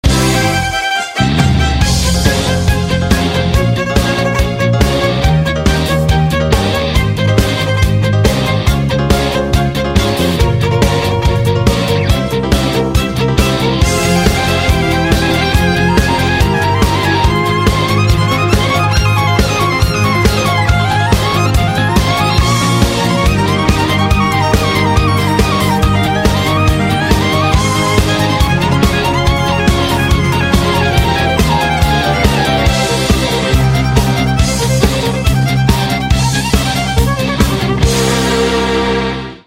• Качество: 128, Stereo
рок-обработка